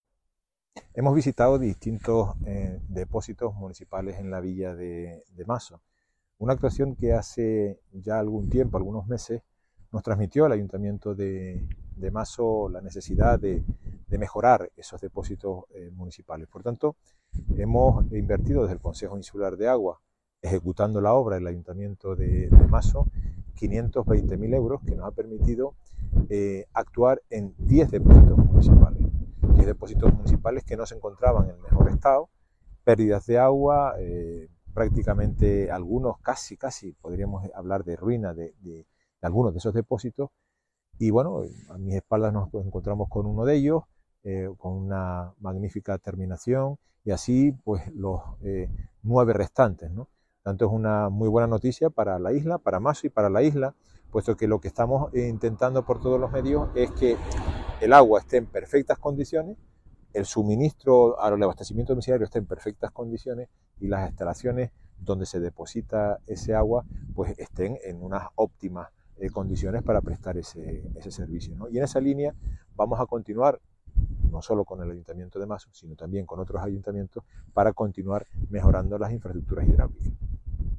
Declaraciones audio Carlos Cabrera obras hidráulicas Mazo.mp3